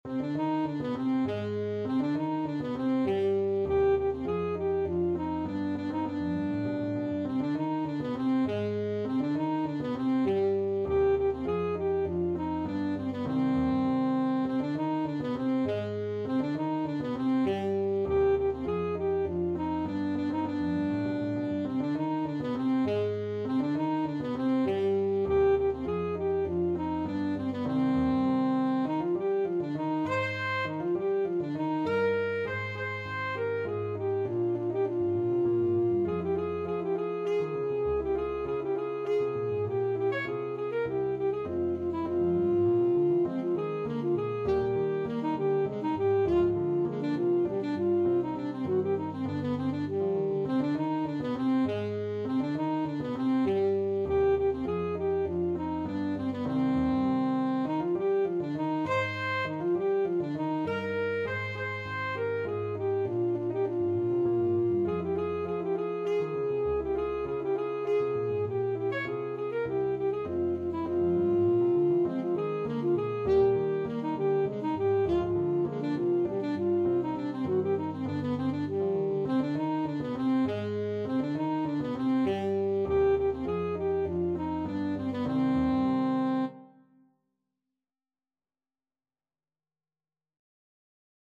Alto Saxophone version
Alto Saxophone
3/4 (View more 3/4 Music)
Allegretto = 100
Classical (View more Classical Saxophone Music)